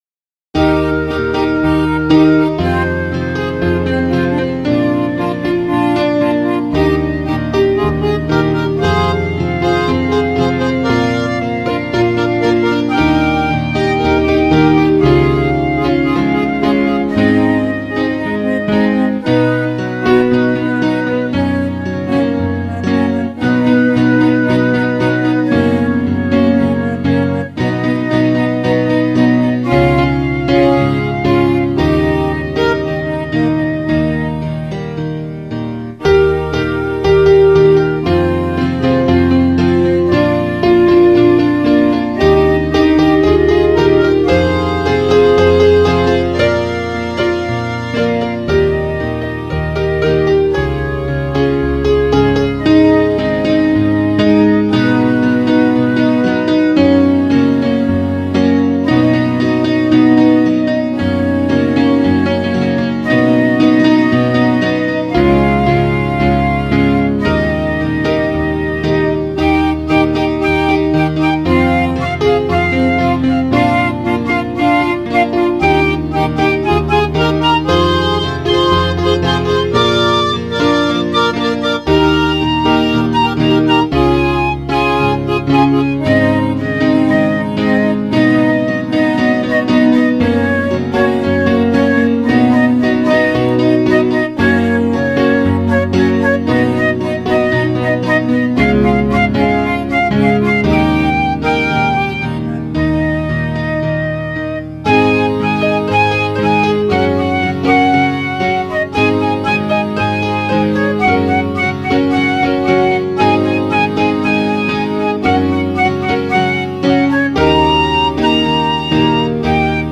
EstiloInstrumental Brasileiro